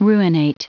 Prononciation du mot ruinate en anglais (fichier audio)
Prononciation du mot : ruinate